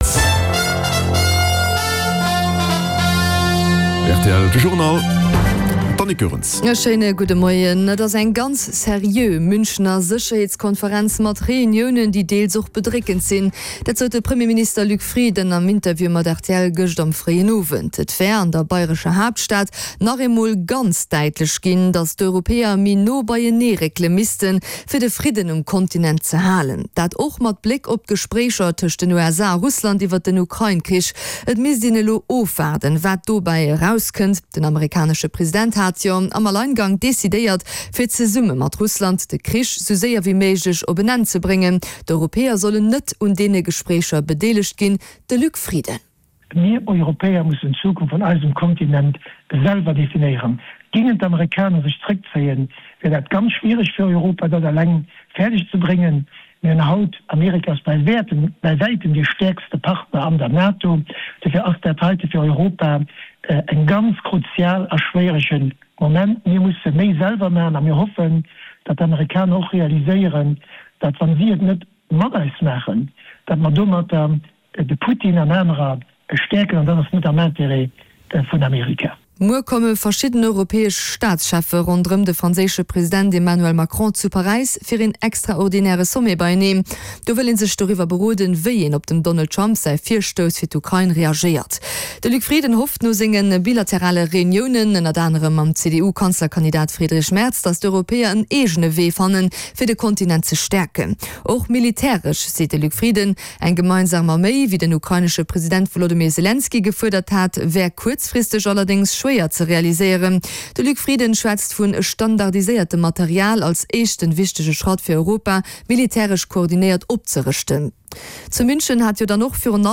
De grousse Journal vun RTL Radio Lëtzebuerg, mat Reportagen, Interviewën, Sport an dem Round-up vun der Aktualitéit, national an international